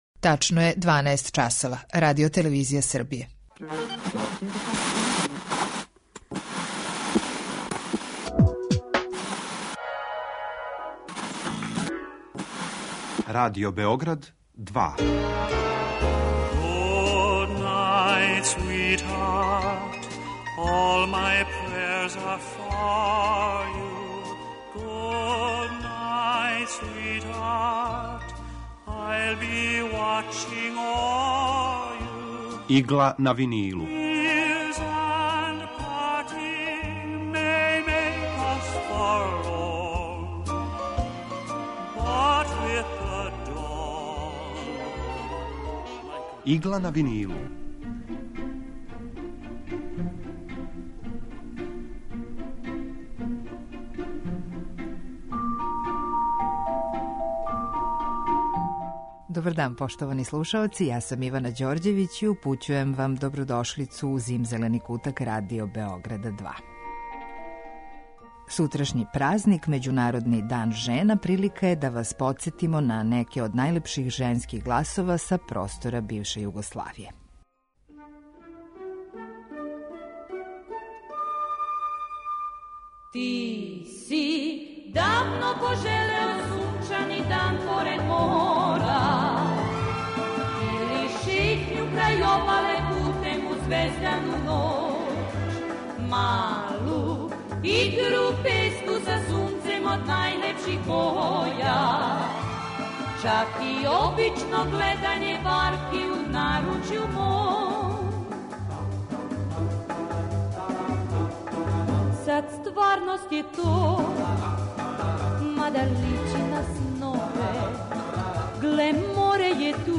У сусрет Међународном дану жена, у данашњој Игли на винилу подсећамо вас на неке од најлепших женских гласова који су обележили југословенску забавну музику.
Евергрин музика